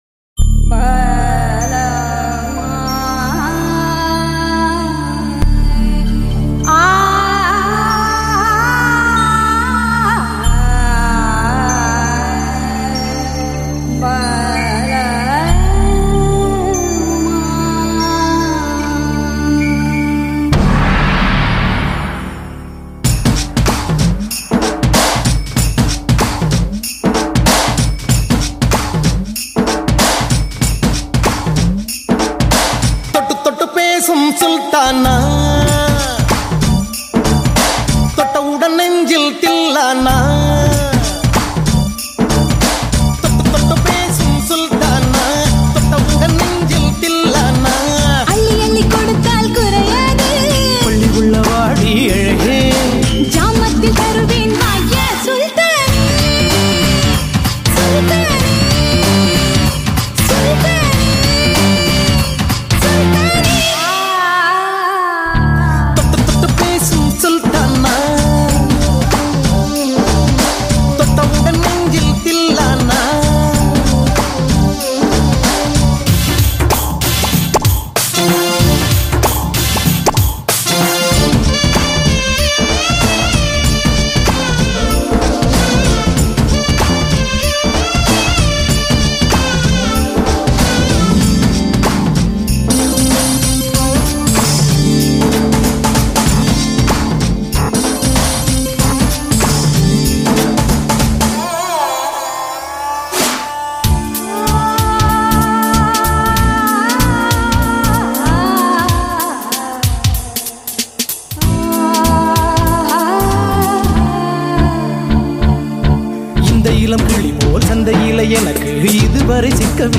Tamil Songs